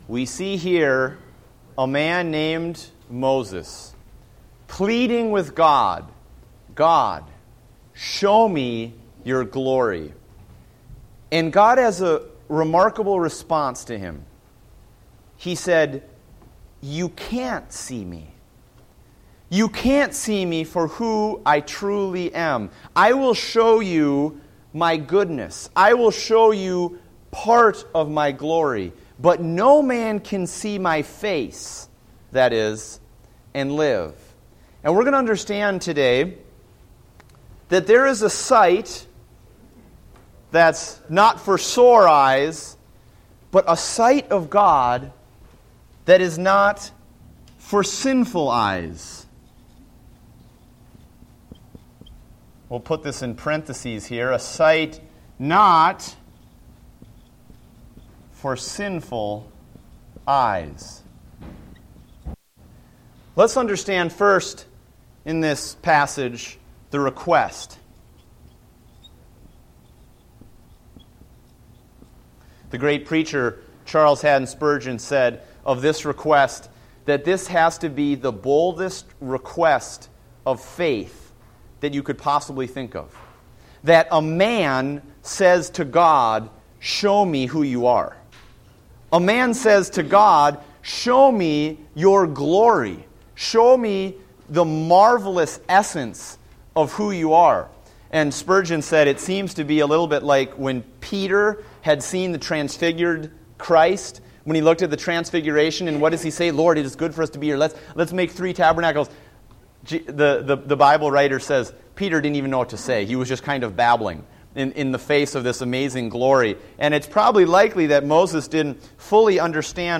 Date: August 10, 2014 (Adult Sunday School)